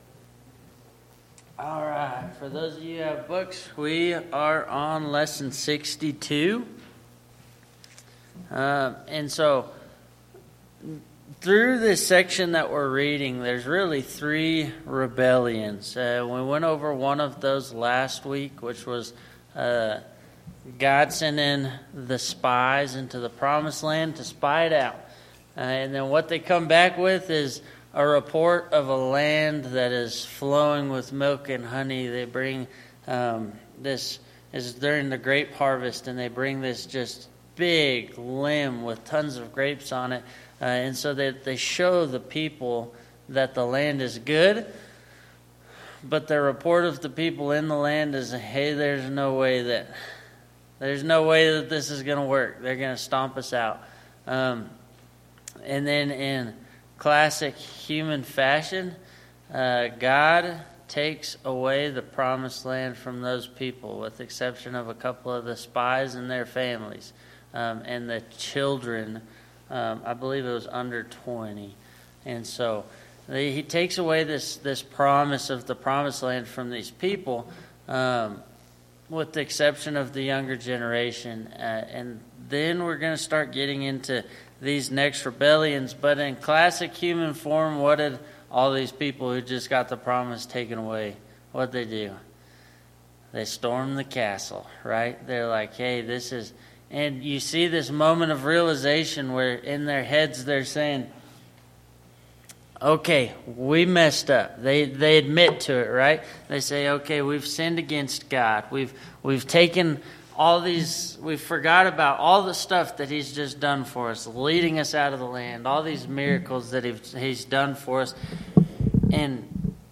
Bible Class 11/17/2024 - Bayfield church of Christ
Sunday AM Bible Class